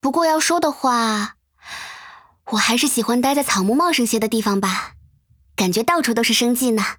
【模型】GPT-SoVITS模型编号097_女-secs
人声克隆